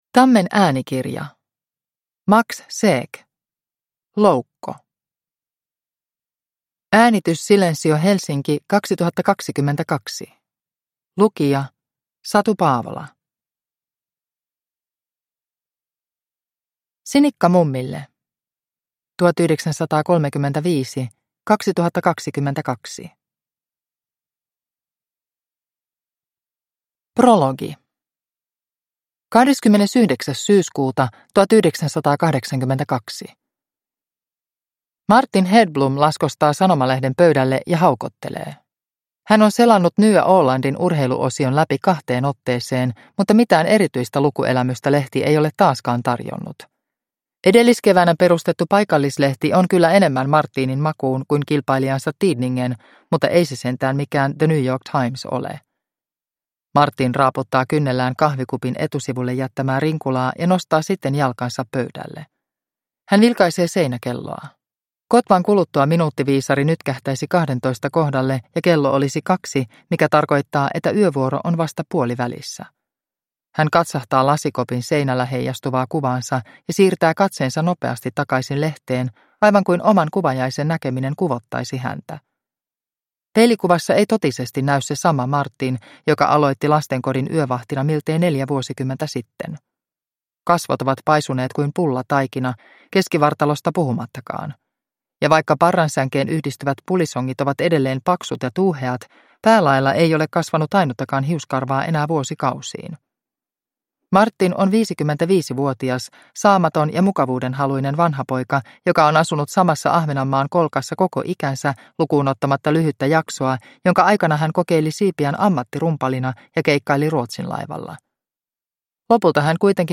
Loukko – Ljudbok – Laddas ner